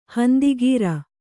♪ handi gīra